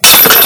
Ching" sound whereas mine just rattles.
ching.wav